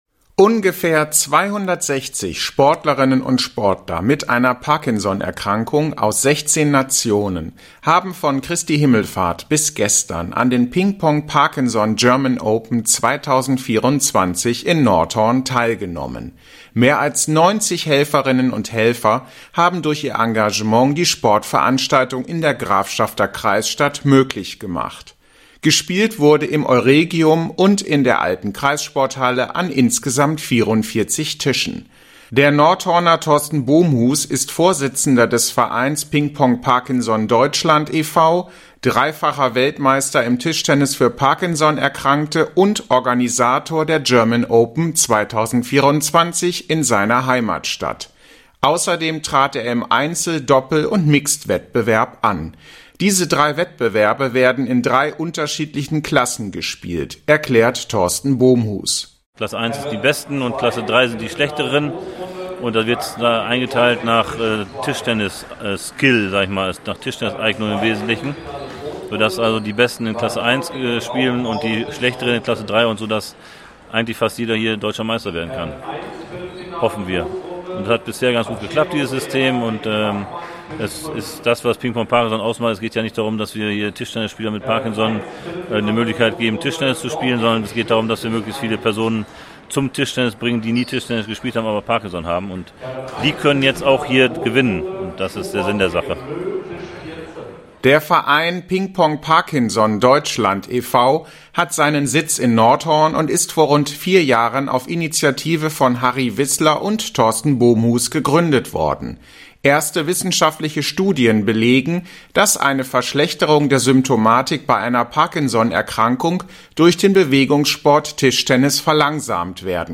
Nordhorn war im Mai 2024 vier Tage lang Austragungsort der PingPongParkinson German Open 2024. Etwa 260 Sportlerinnen und Sportler mit einer Parkinson-Erkrankung haben an den Tischtennis-Wettkämpfen in verschiedenen Klassen im Euregium und der alten Kreissporthalle in Nordhorn teilgenommen.